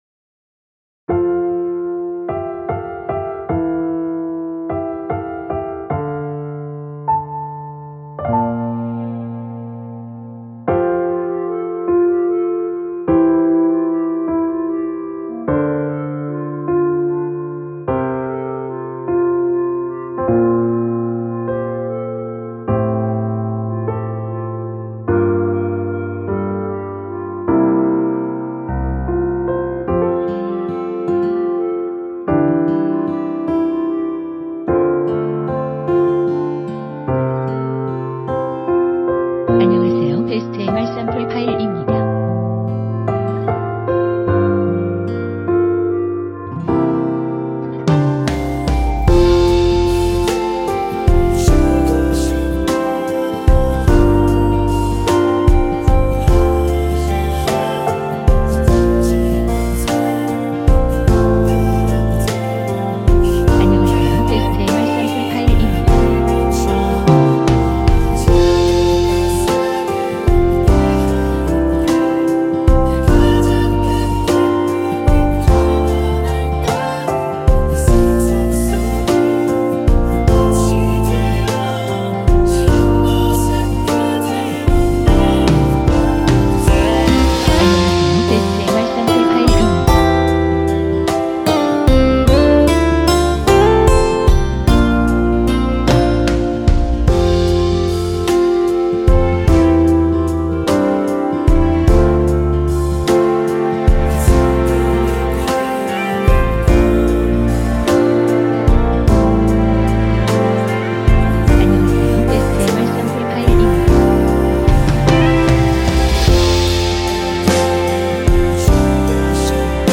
원키 멜로디와 코러스 포함된 MR입니다.(미리듣기 확인)